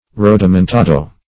Rodomontado \Rod`o*mon*ta"do\